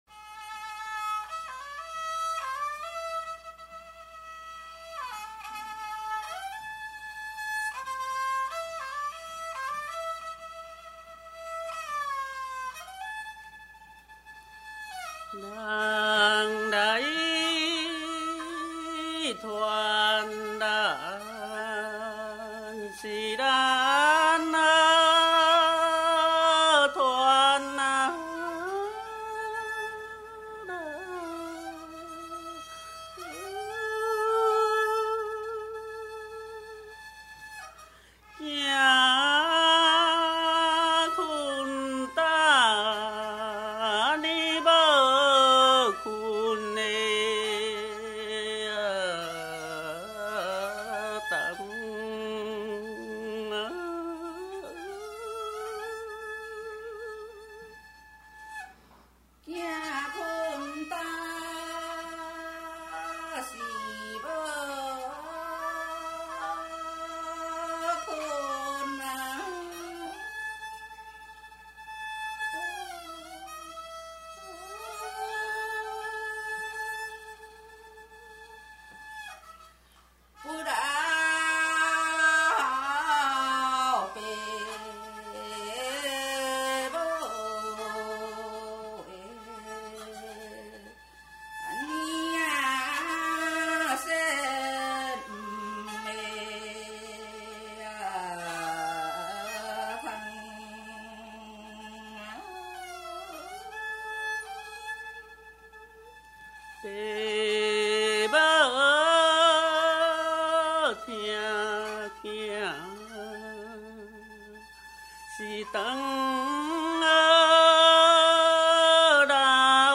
◎制作群 ： 演出：月琴,壳仔弦｜
18首原汁原味的素人歌声，在粗哑中满怀真性情，那是上一代的回忆，这一代的情感，下一代的宝藏！
弹唱